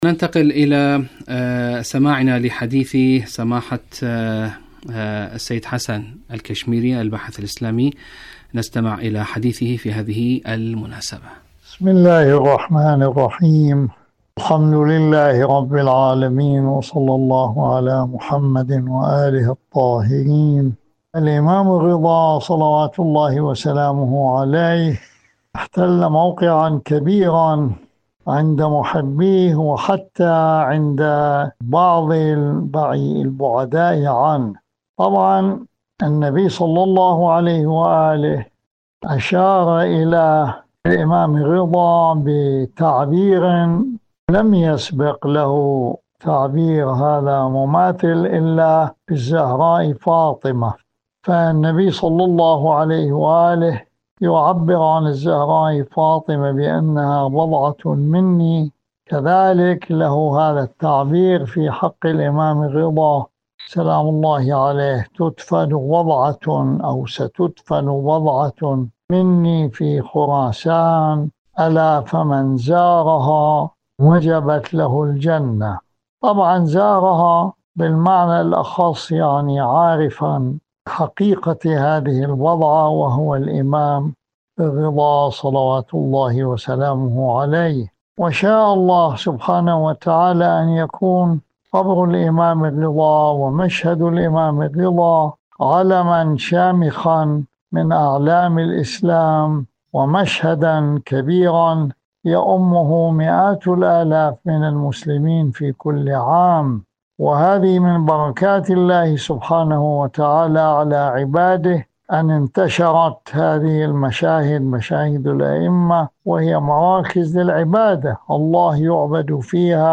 سلطان طوس.. مقابلة
إذاعة طهران- شهادة الإمام الرضا عليه السلام: مقابلة إذاعية